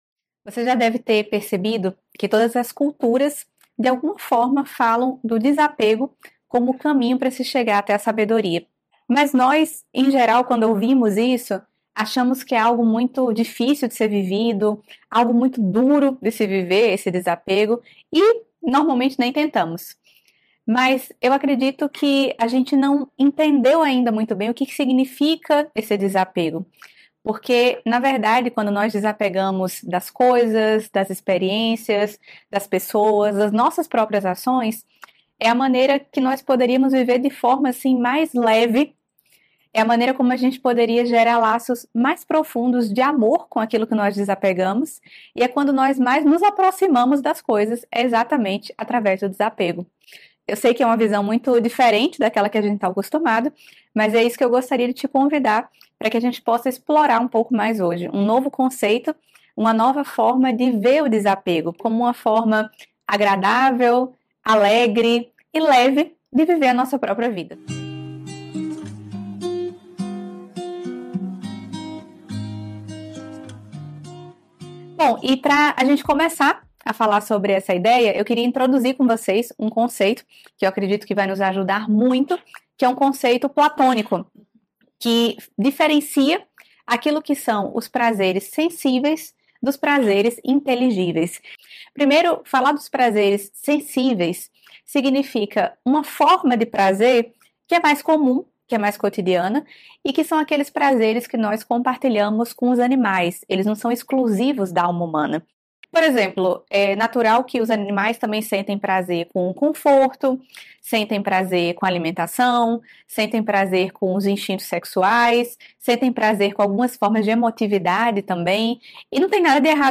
Palestras Filosóficas Nova Acrópole